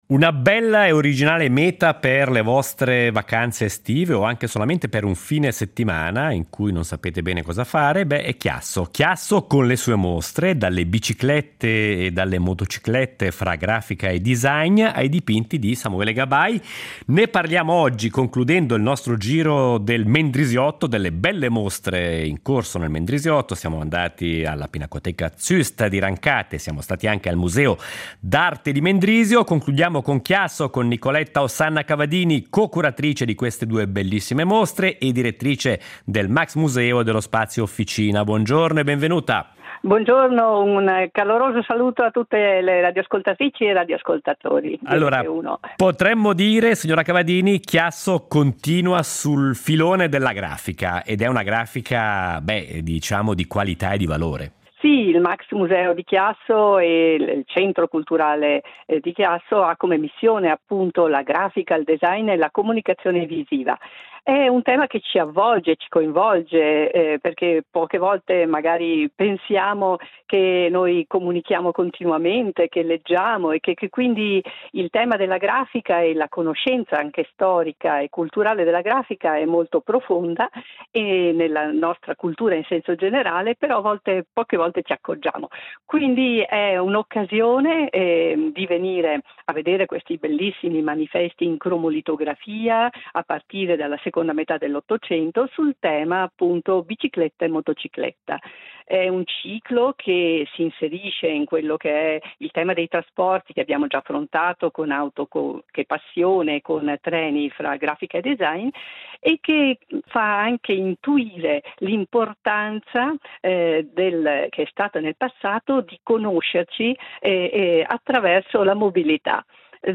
Ne parliamo con: